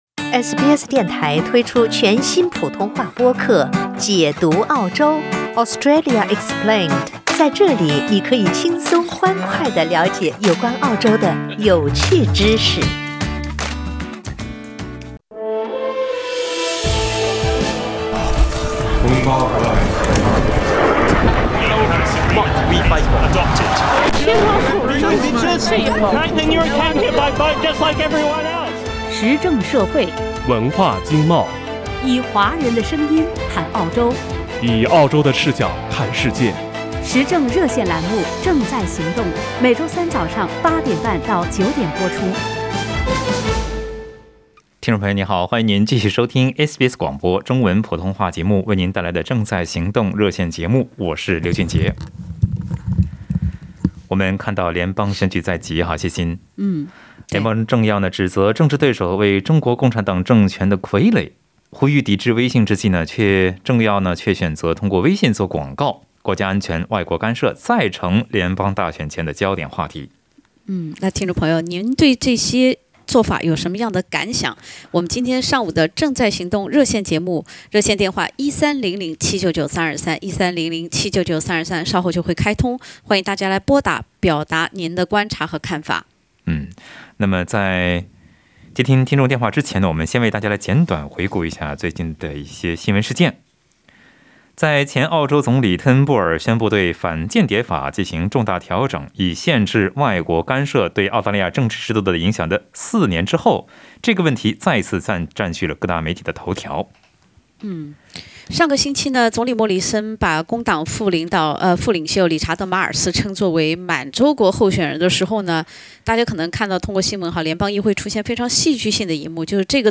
本文解答你的所有疑惑 在本期《正在行动》热线节目中，听友们就外国干涉再成联邦大选前焦点话题表达了自己的看法。有听友认为，外国干涉切实存在，华人应该看得更长远；也有听友认为，这是自由党推行的“恐吓”选举手段，华人利益最终受到损害。